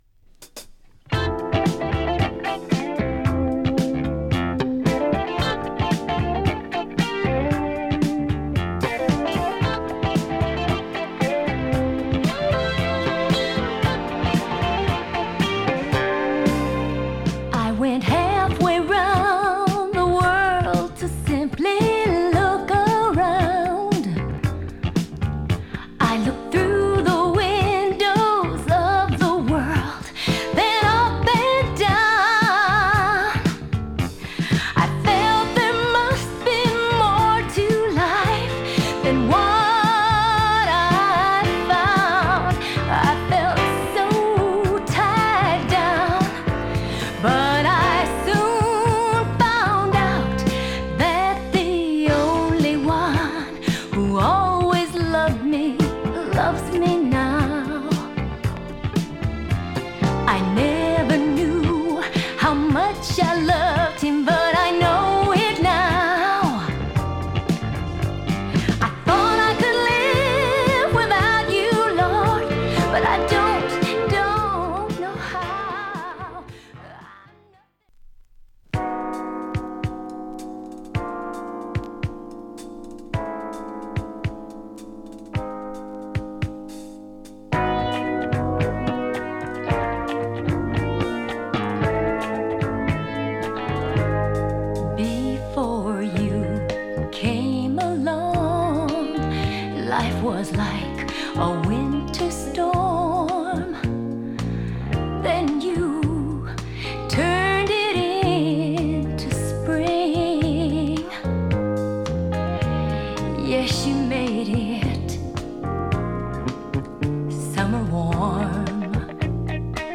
男女ユニットによる激レアCCM〜レリジャス作品。